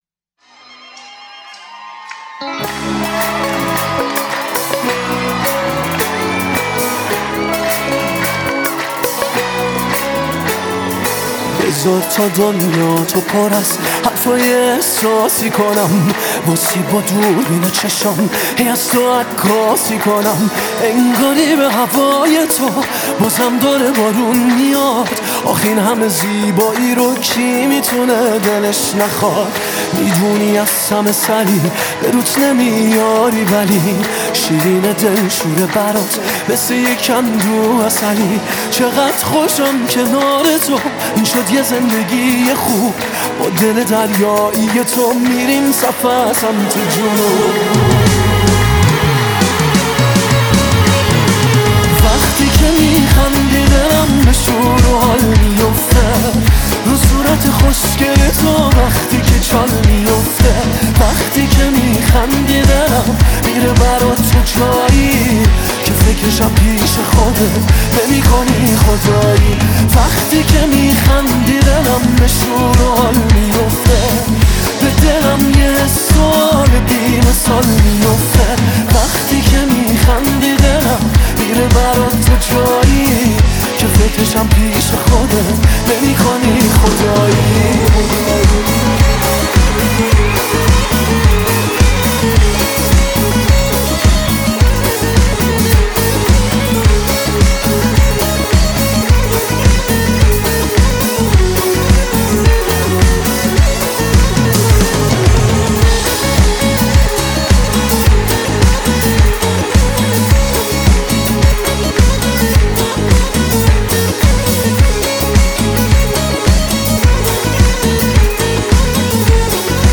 Concert Version